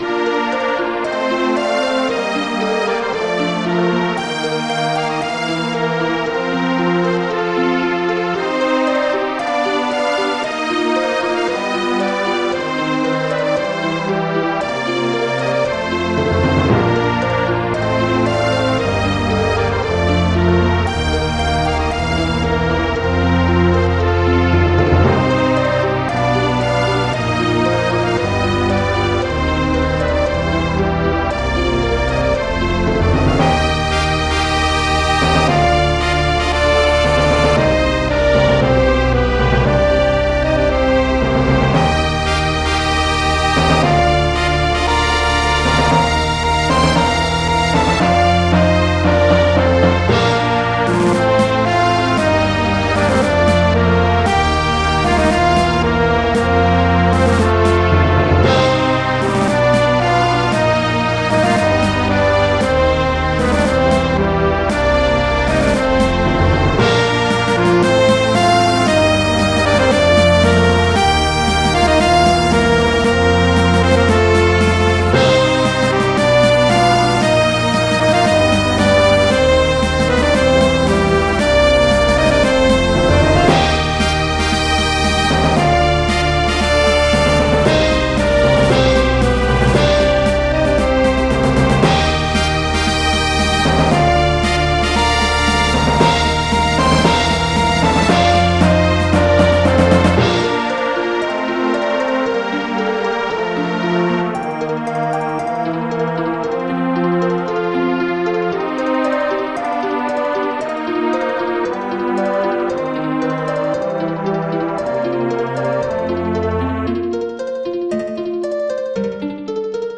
MainTheme16bitFF_loop.ogg